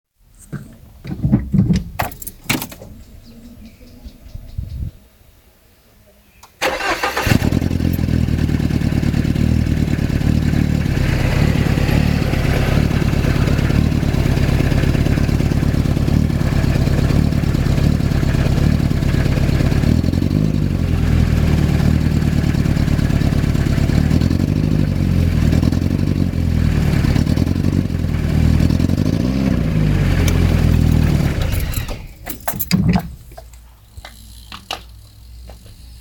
Quand je démarre ma voiture, mon moteur ne tourne pas rond, c'est comme si il tournait sur 3 cylindre, comme si un injecteur ne fonctionnait pas, quelque chose n'est pas synchro, quand j'appuie sur la pédale d'accélérateur on s'appercoit que ce n'est pas le bruit d'un moteur qui tourne bien, à écouter la bande son ici